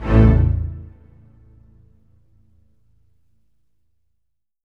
ORCHHIT A1-L.wav